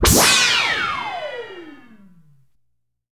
RICOCHET.wav